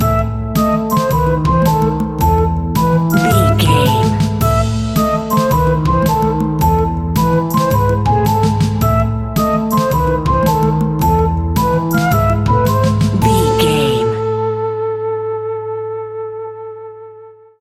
Aeolian/Minor
scary
ominous
haunting
eerie
playful
double bass
piano
drums
brass
spooky
horror music